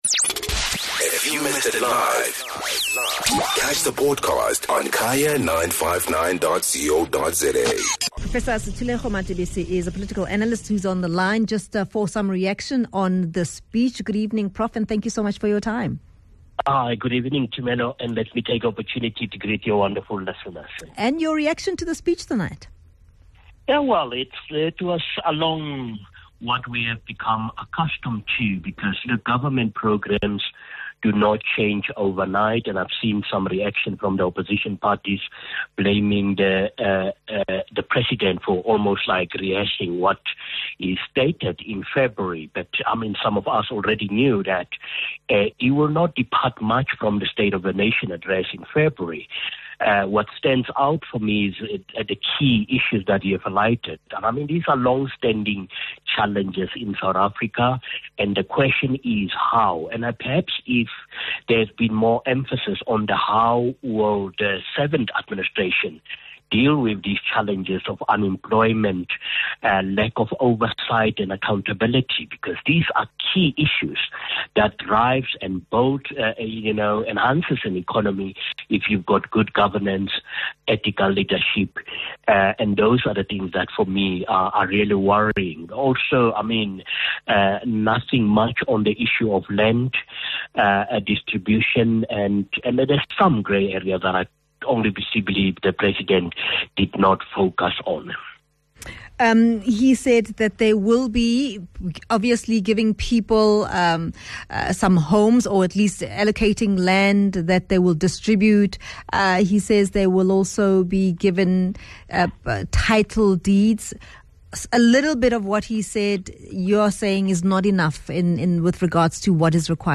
Political Analyst